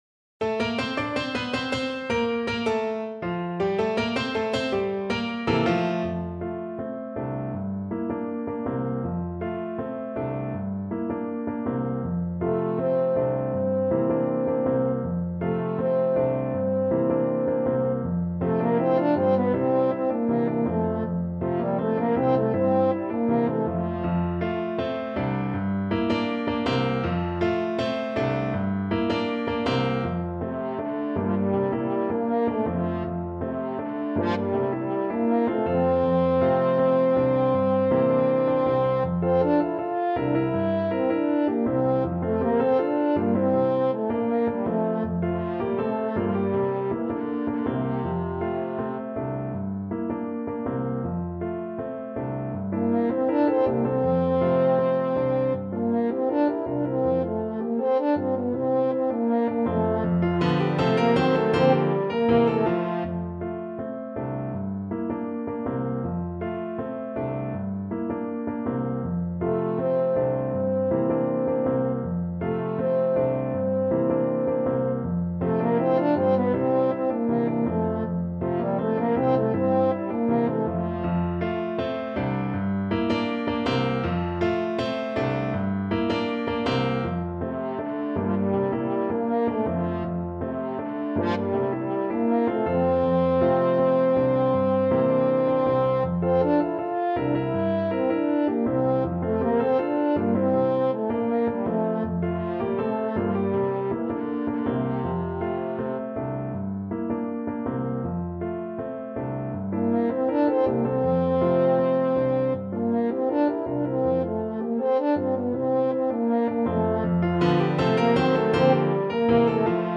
Allegretto =80
2/2 (View more 2/2 Music)
Classical (View more Classical French Horn Music)
Cuban